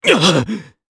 Clause_ice-Vox_Damage_jp_02.wav